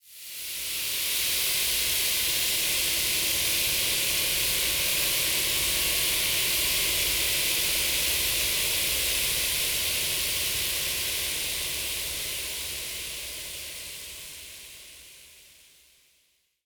Index of /musicradar/shimmer-and-sparkle-samples/Filtered Noise Hits
SaS_NoiseFilterD-05.wav